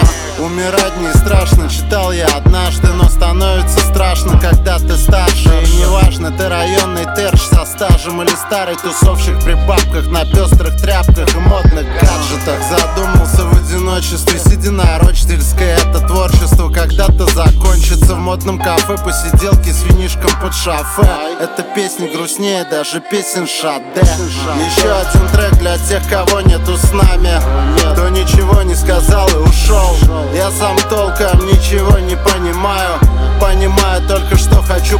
Hip-Hop Rap